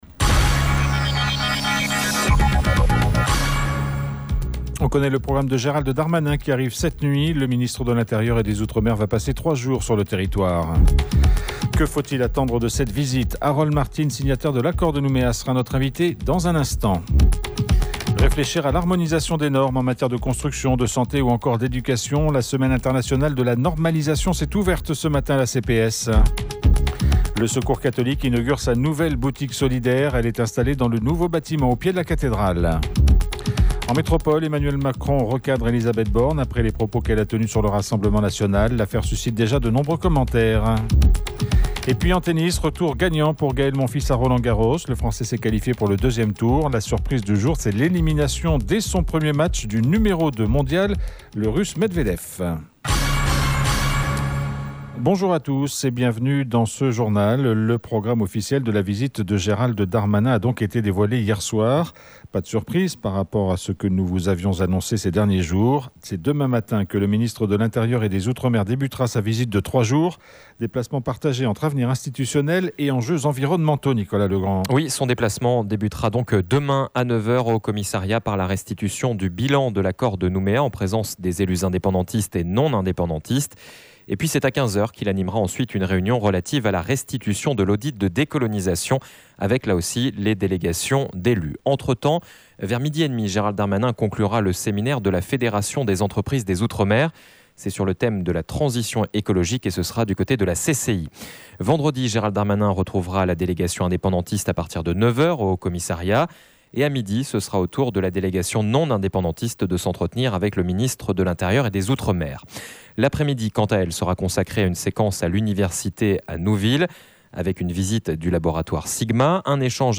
Harold Martin, signataire de l'Accord de Nouméa était l’invité du journal pour parler de cette visite.